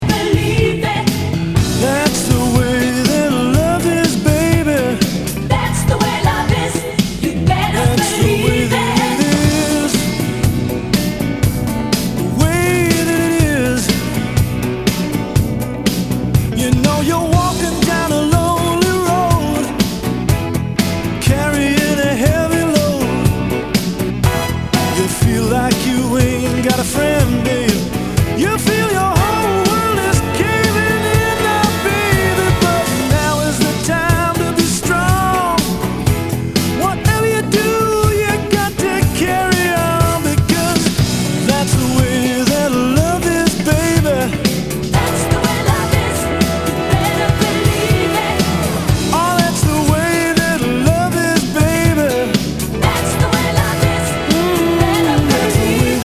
ROCK/POPS/INDIE
ナイス！Pop Rock！